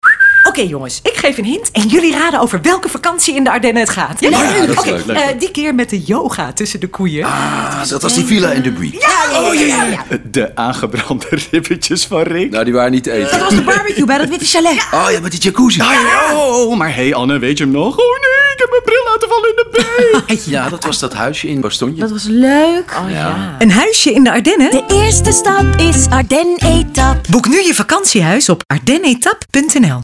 Commercials: